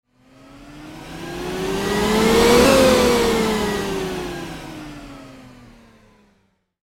Speeding Up and Slowing Down Sound Effect: Futuristic Transition
Futuristic-style electronic digital transition sound. Acceleration and deceleration.
Speeding-up-and-slowing-down-sound-effect.mp3